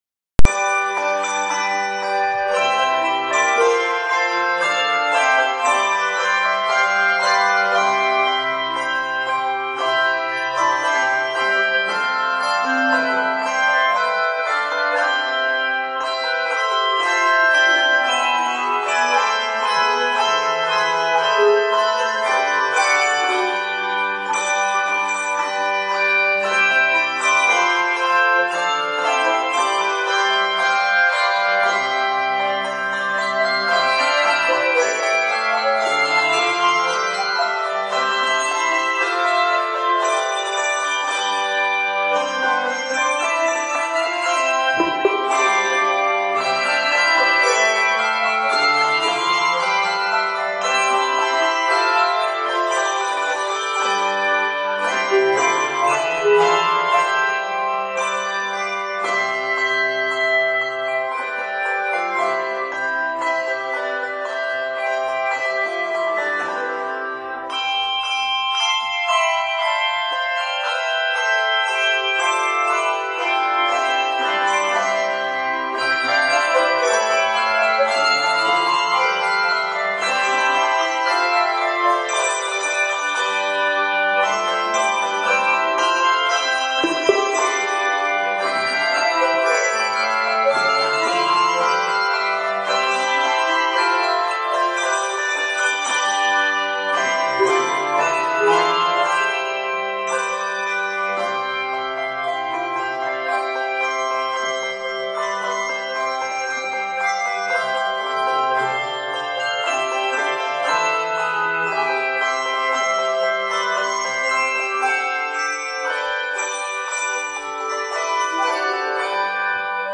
" it is 85 measures long and is scored in C Major.